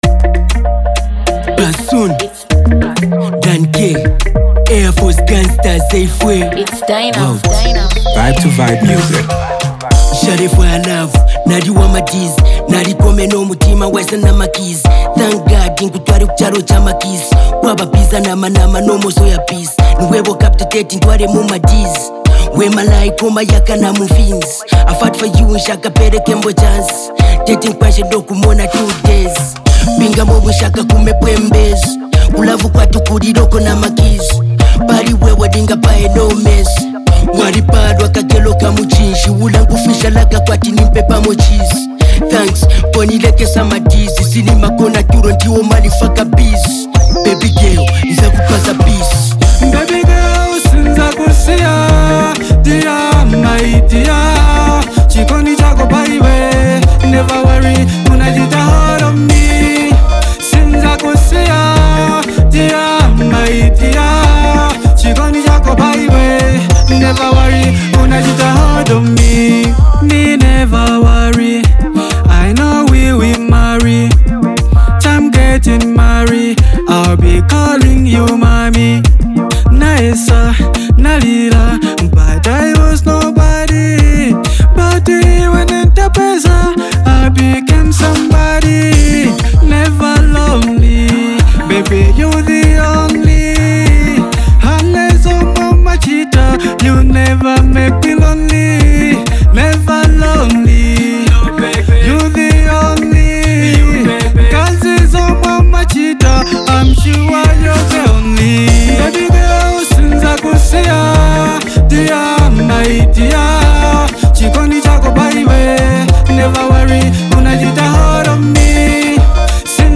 a hard-hitting and emotional track